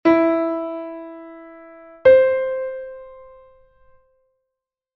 Listening to ascending and descending intervals